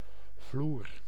Ääntäminen
IPA: [ˈboːdn̩] IPA: /ˈboː.dǝn/